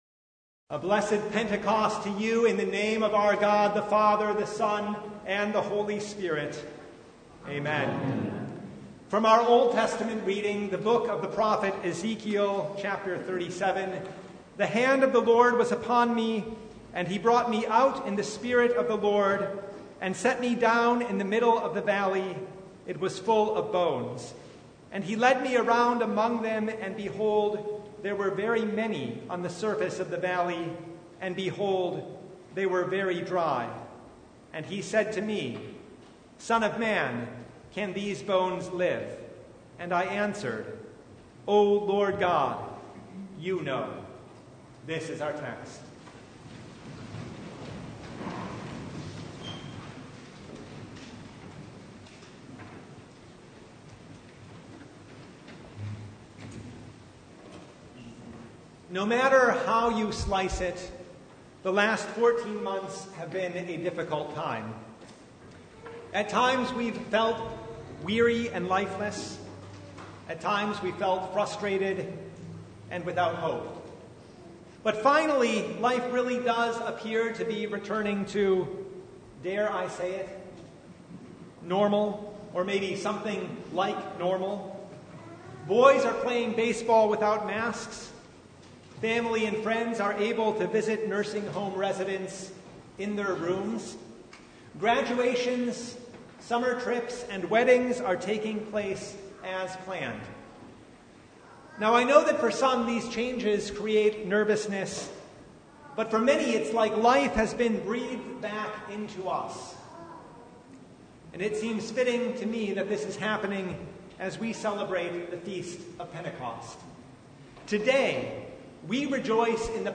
Service Type: The Feast of Pentecost
Sermon Only « The Feast of Pentecost